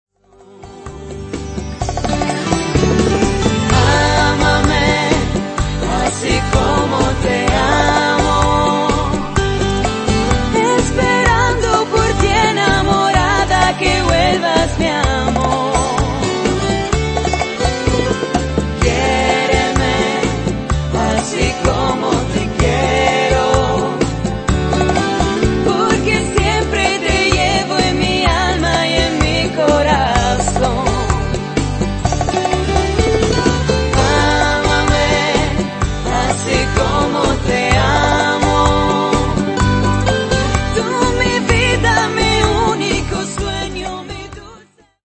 bachata